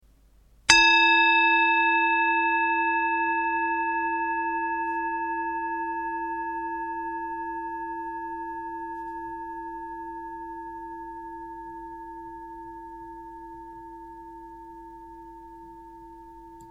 Tibetische Klangschale SCHULTERSCHALE 542g KMF8B
Tibetische gravierte Klangschale - SCHULTERSCHALE
Grundton: 333,81 Hz
1. Oberton: 904,37 Hz